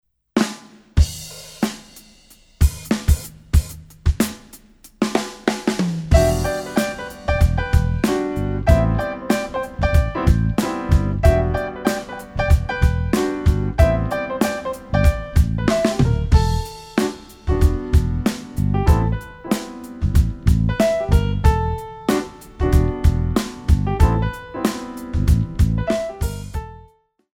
4 bar intro
up-tempo
Jazz-Funk / Tap